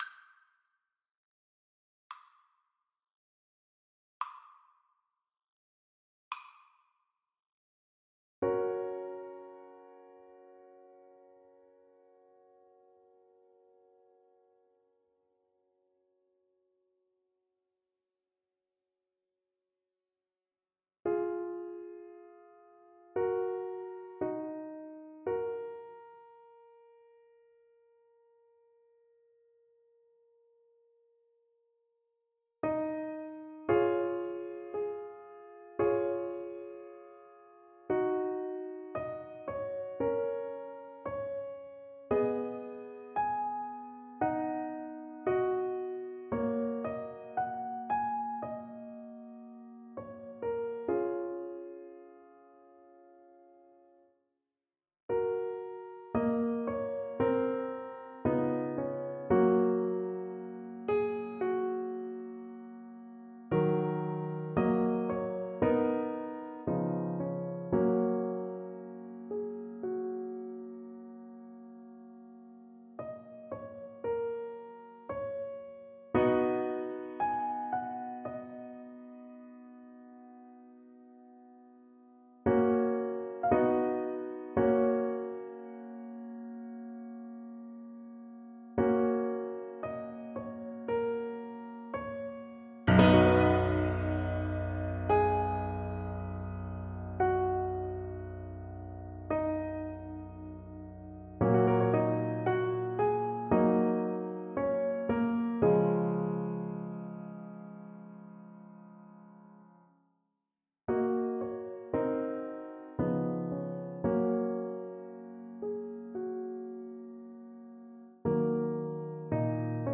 Adagio = 50
4/4 (View more 4/4 Music)
Classical (View more Classical Soprano Voice Music)